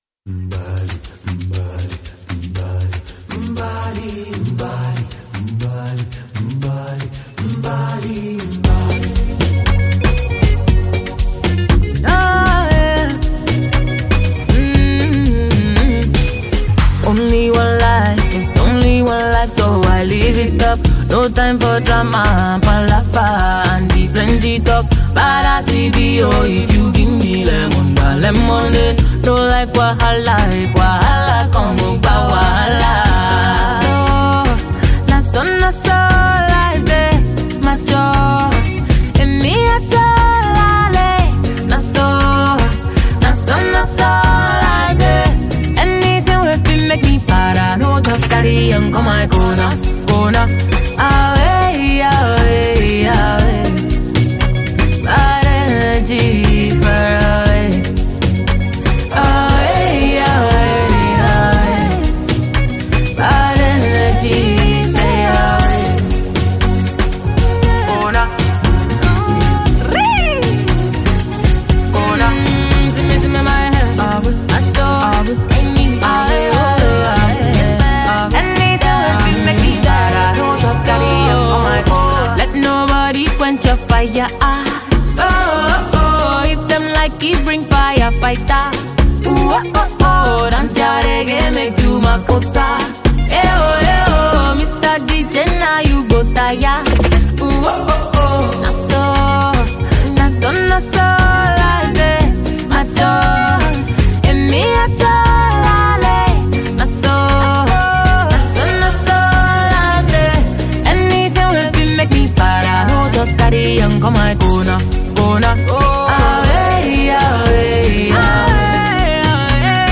Afro pop
With its polished production powerful hooks